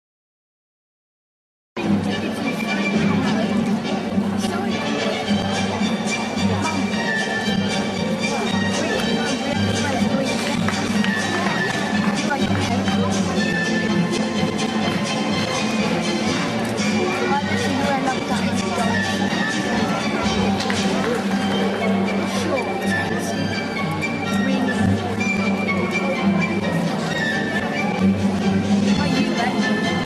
Это действительно гуцульский танец - "Гуцулка".